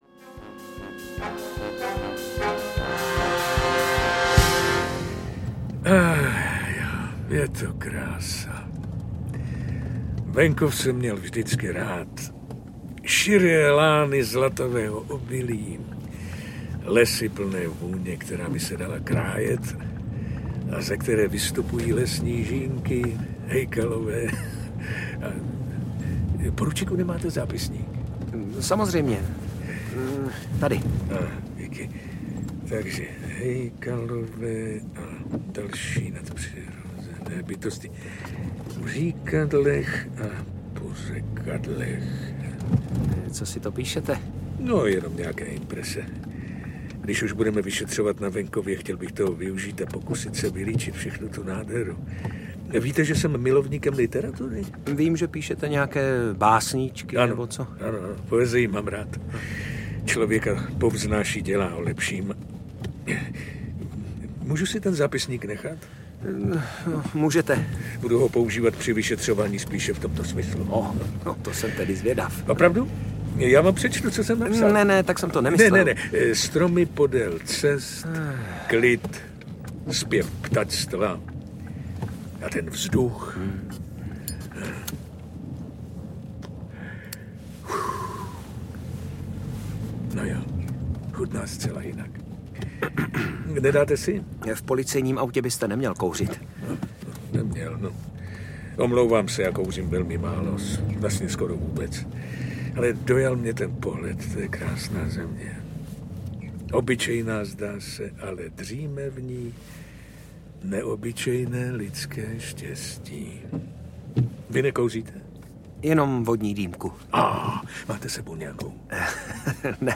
Vraždy jako z pohádky audiokniha
Ukázka z knihy